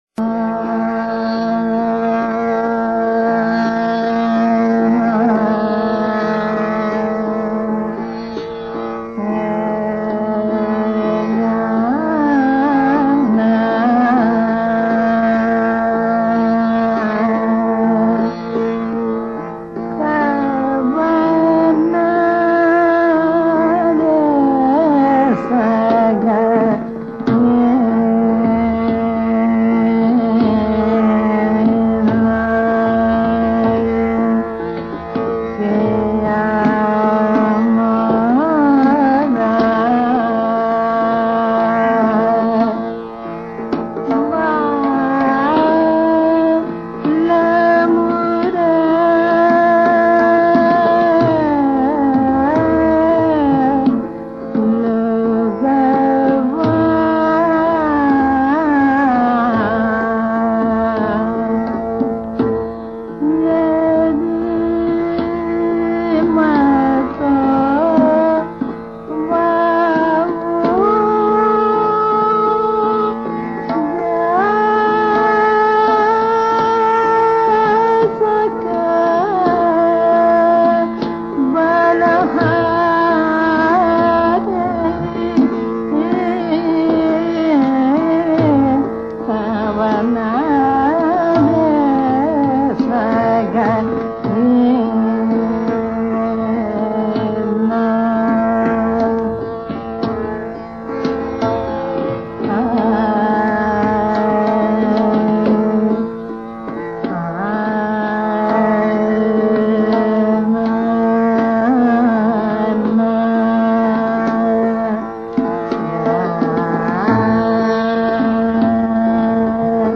Thanks for a rare recording of a fabulous singer